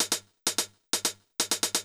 CLF Beat - Mix 11.wav